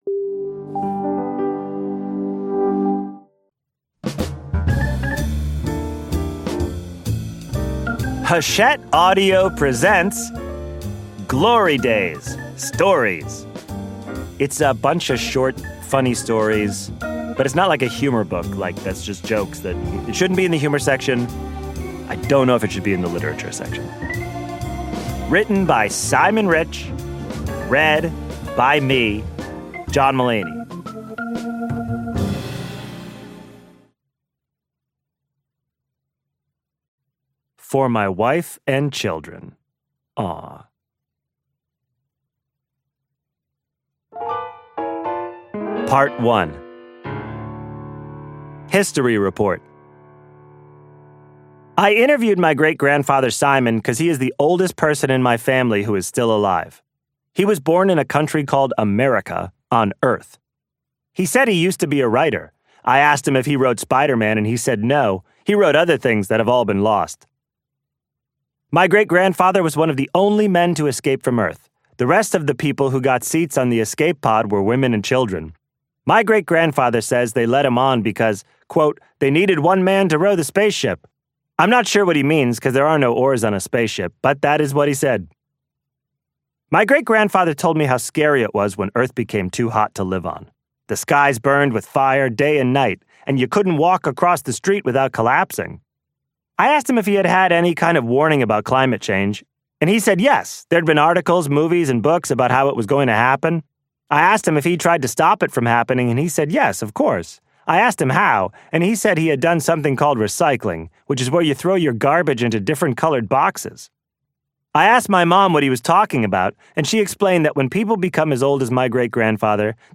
Glory Days： Stories by Simon Rich · Audiobook preview [oOBLfNTtWNQ].mp3